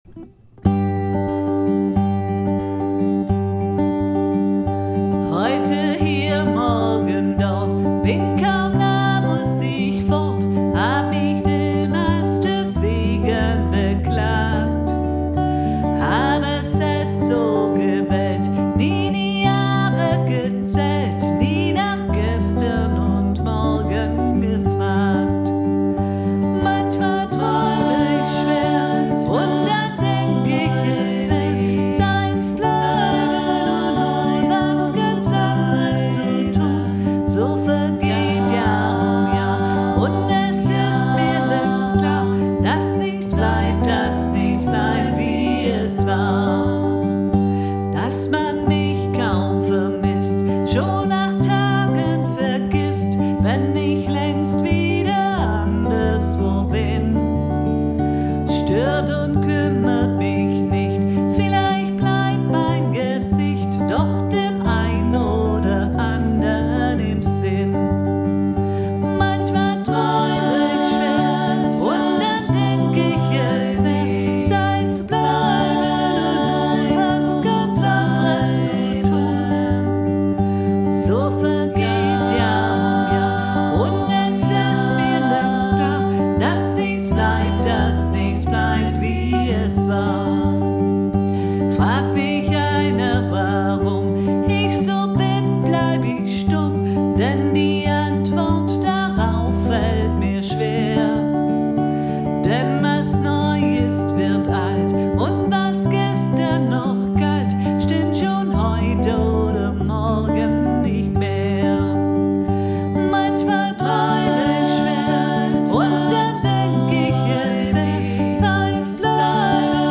Offenes Singen Heute hier morgen dort alle